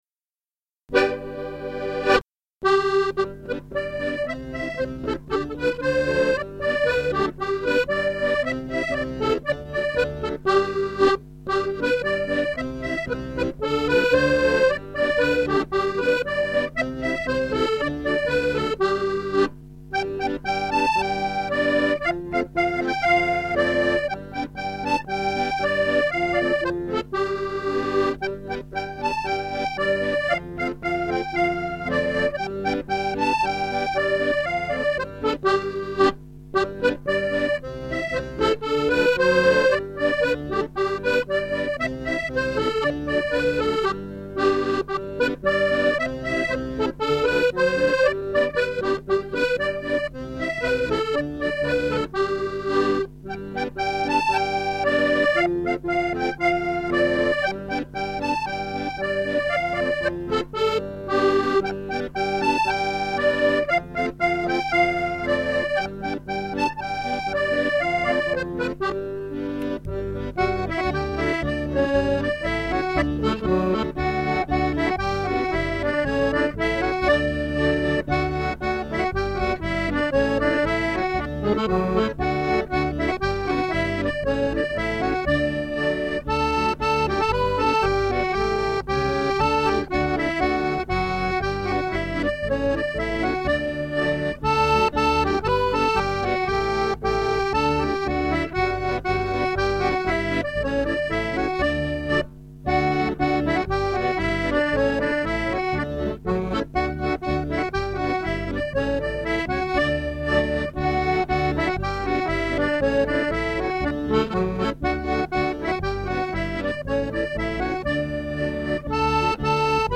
Music - 32 bar reels or jigs
auidio/6 x 32 reels.mp3